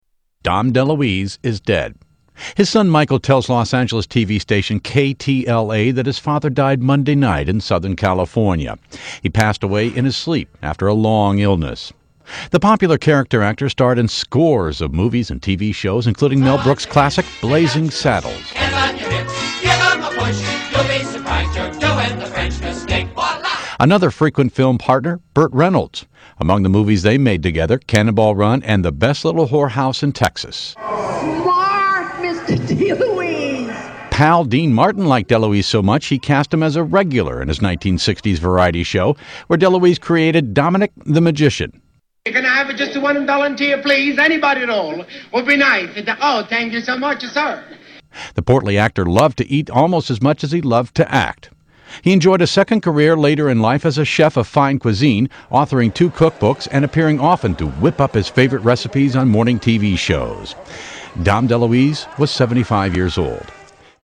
Dom Deluise death news report